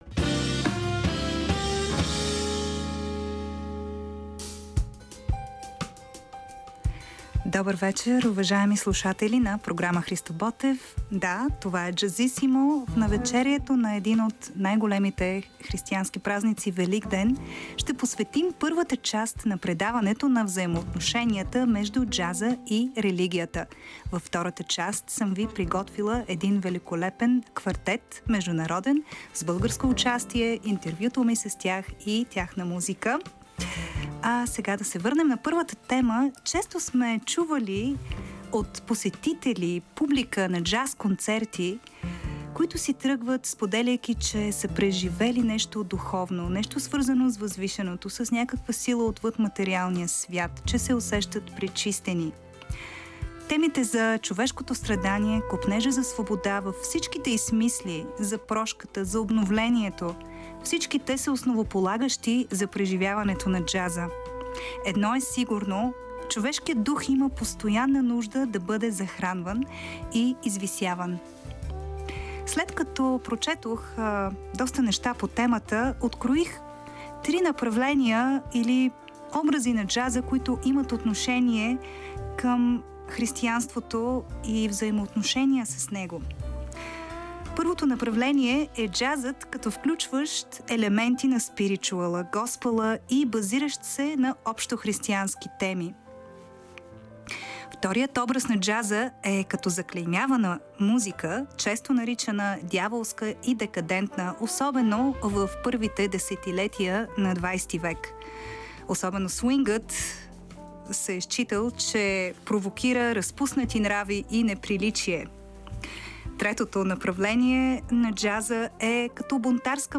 Първата част е посветена на взаимовръзките между джаза и вярата и баланса между бунтарство и свободолюбивост, от една страна, и силна духовна основа и стремеж за извисяване, от друга. Във втората част гостуват музикантите, основатели и движеща сила на забележителната международна джаз формация Blazin’ Quartet.